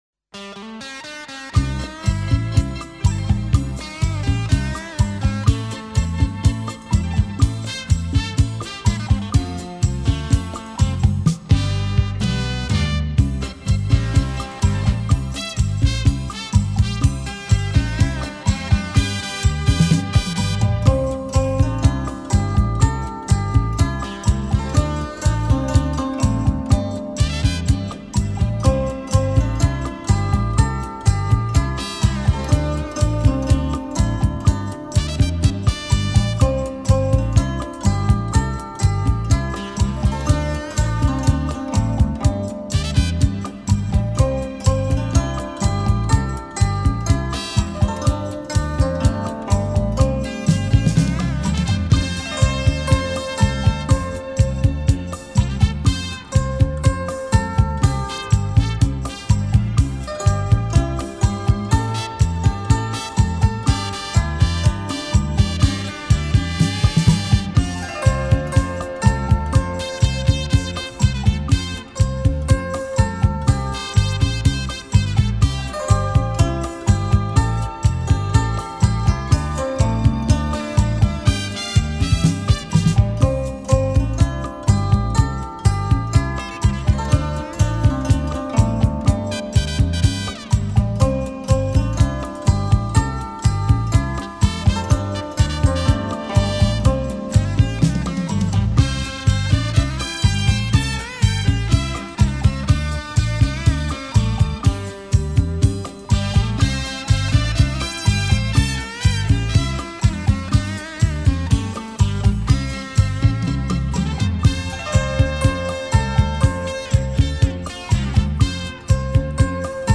爽心的民族音乐
熟悉的旋律，动听的音符， 还有散发出的浓浓情怀，袅袅中，把人带入过去美好的时光。